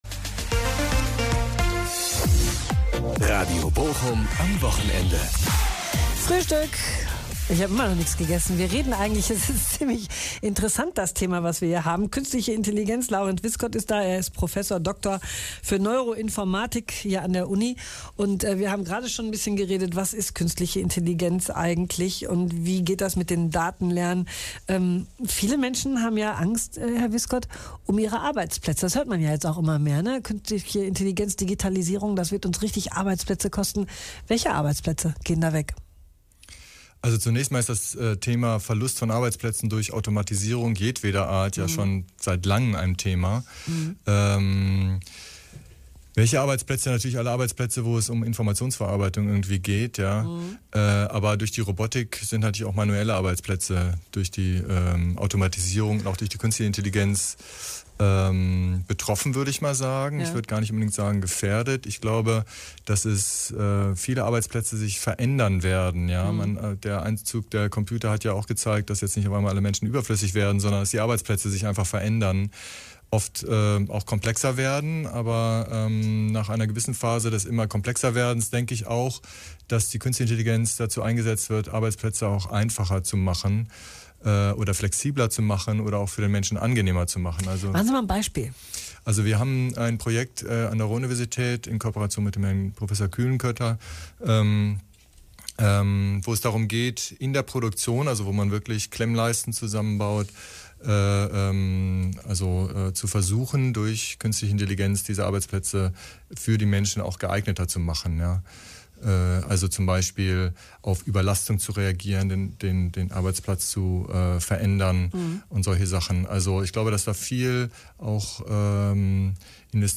zum Sonntagsfrühstück im Radio Bochum eingeladen und hat den Hörern Künstliche Intelligenz näher gebracht.